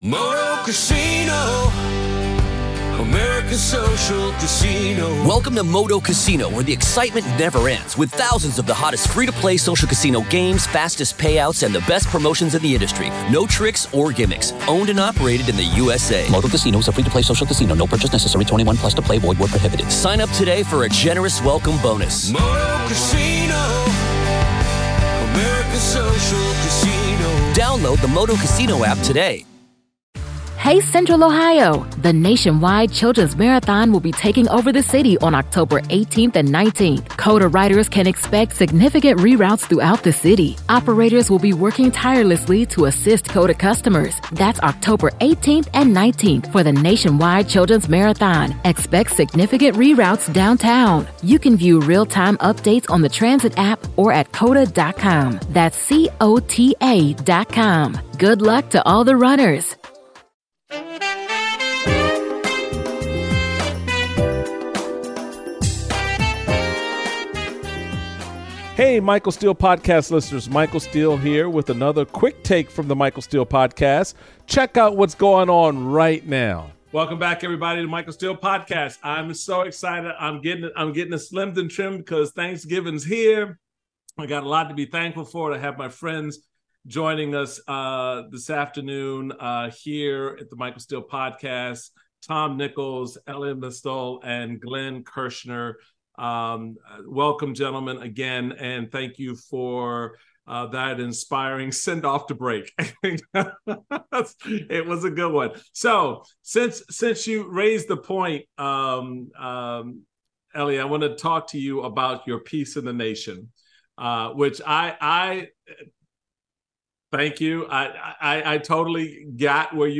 Michael is joined by Tom Nichols, Elie Mystal and Glenn Kirschner for a barbershop discussion on current events and politics. They discuss why Democrats are better off without Joe Manchin, how Trump passed the line into fascism and what the Supreme Court "Code of Ethics" really means.